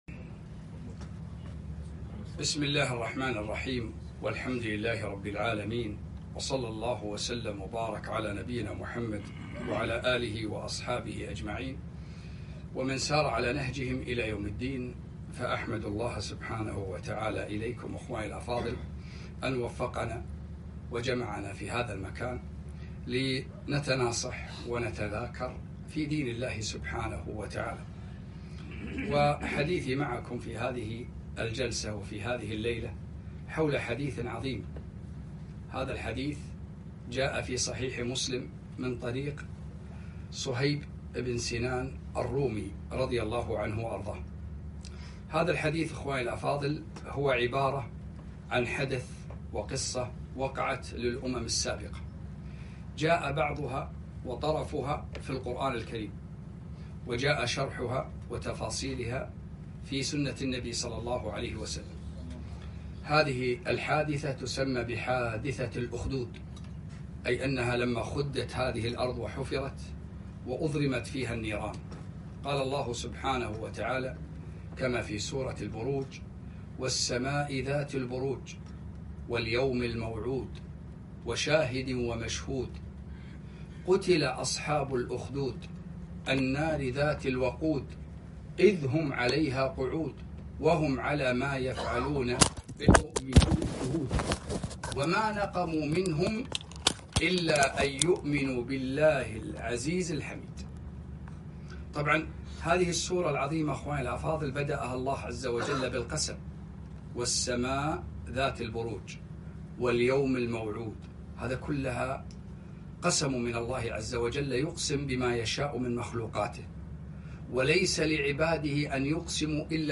كلمة - حادثة الأخدود